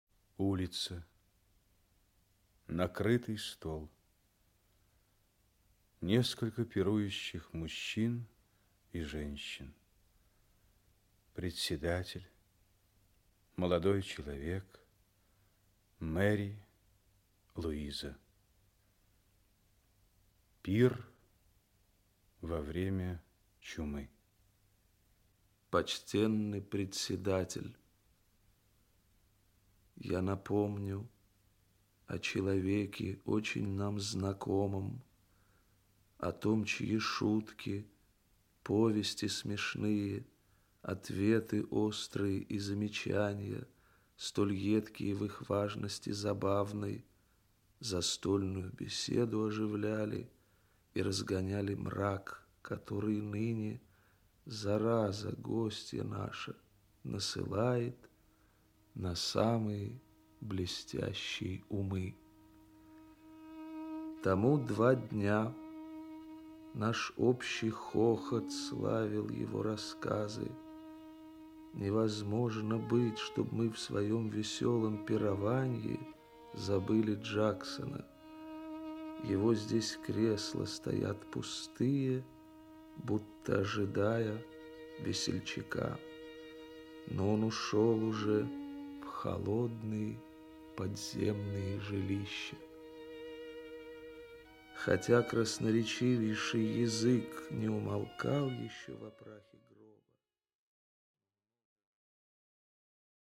Аудиокнига Пир во время чумы | Библиотека аудиокниг
Aудиокнига Пир во время чумы Автор Александр Пушкин Читает аудиокнигу Актерский коллектив.